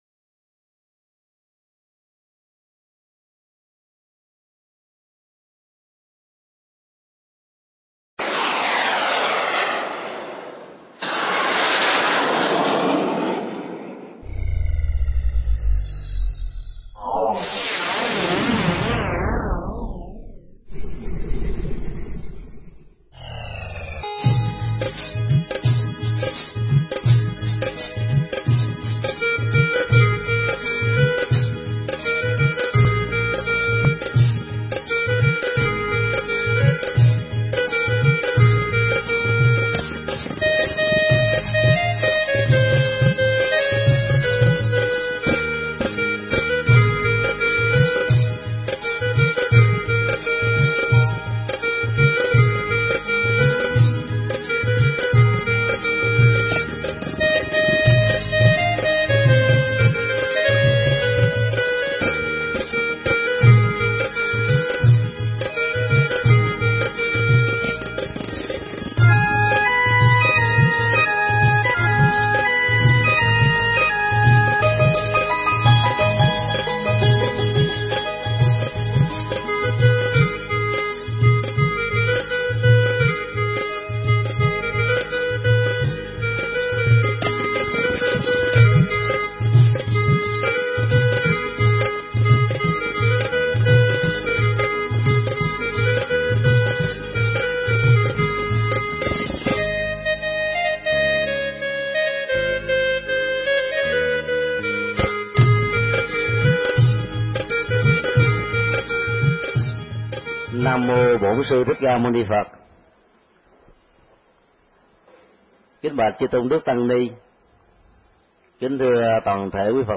Mp3 Pháp thoại Phật Tích Ấn Độ 1
giảng trong chuyến thăm và chiêm bái Phật Tích tại Ấn Độ